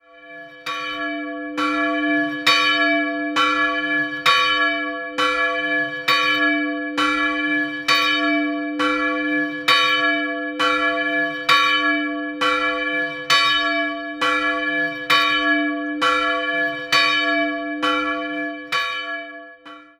Die Glocke entstand bereits im 14. Jahrhundert und hing ursprünglich in der Pfarrkirche im benachbarten Pollanten. Mit der Anschaffung der neuen Glocken dort nach dem Zweiten Weltkrieg wurde sie nicht mehr benötigt und kam dann nach Grubach.